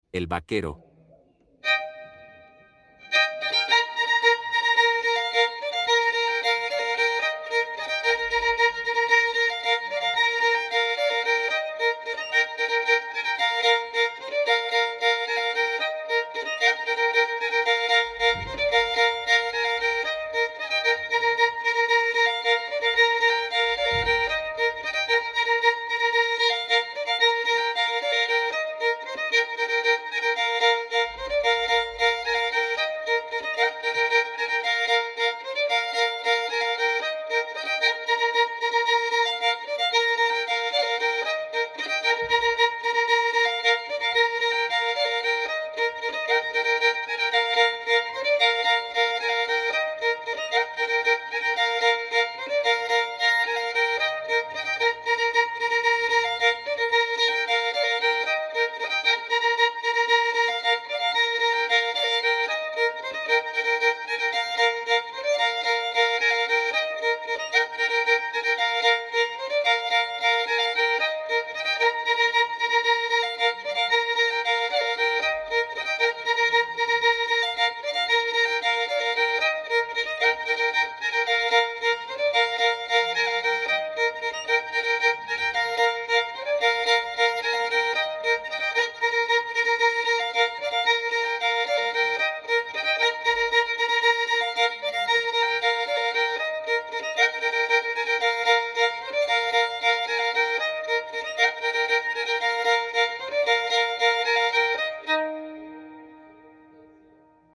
APRENDE A TOCAR SONES DE DANZA EN VIOLÍN.